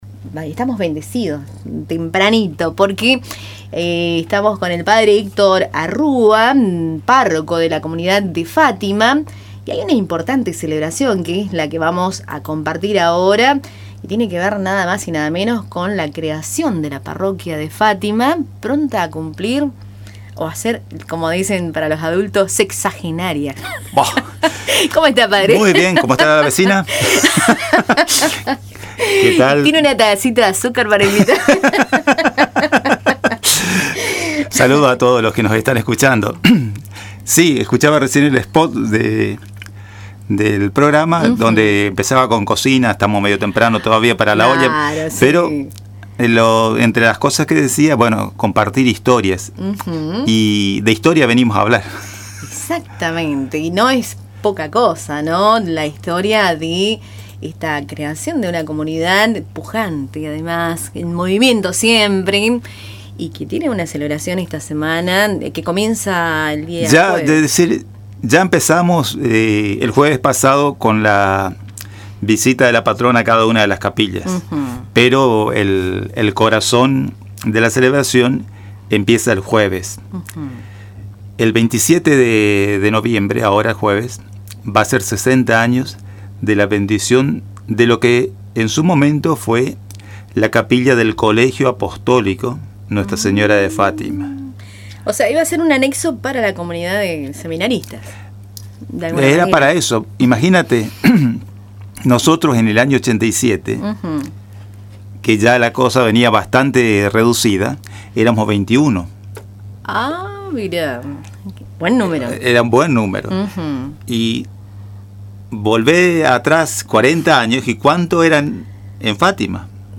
dialogó con Radio Tupambaé y brindó detalles de la programación especial que comenzará este jueves 27 de noviembre, día en que se conmemora oficialmente la creación de lo que entonces fue la capilla del Colegio Apostólico Nuestra Señora de Fátima.